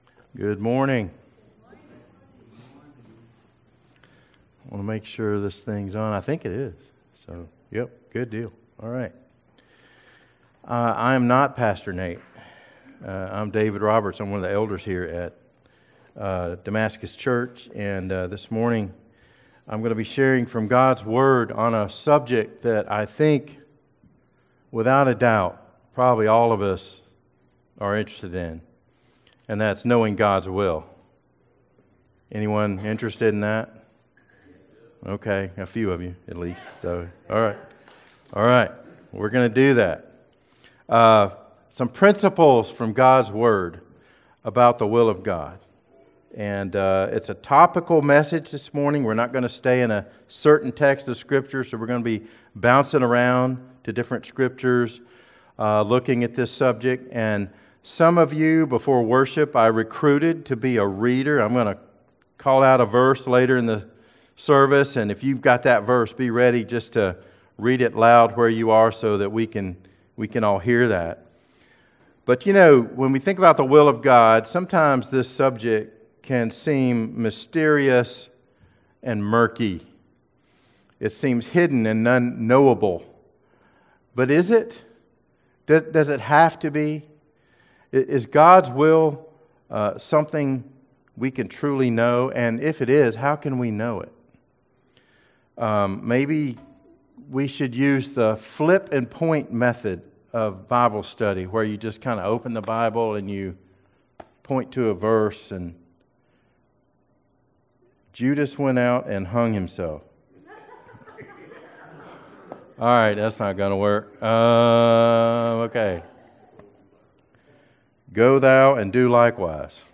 Sermons | Damascus Church